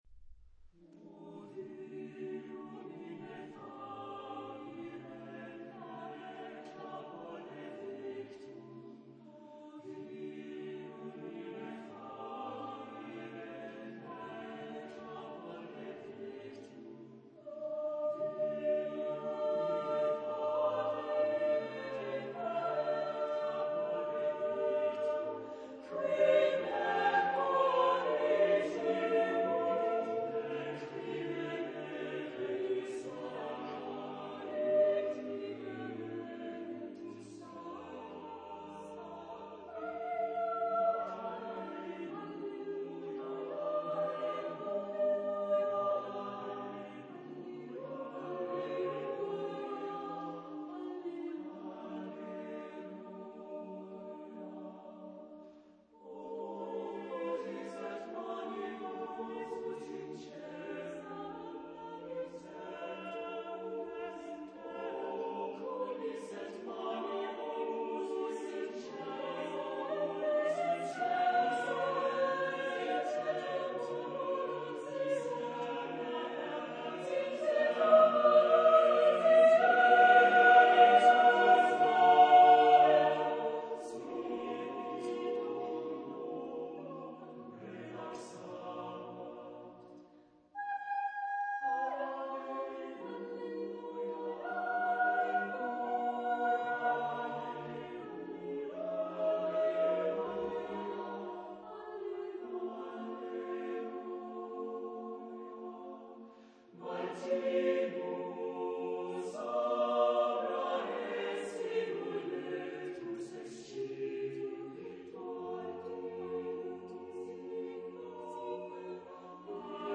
Genre-Style-Forme : Hymne (sacré) ; Sacré
Type de choeur : SATB + SATB  (8 voix Double Chœur )
Consultable sous : 20ème Sacré Acappella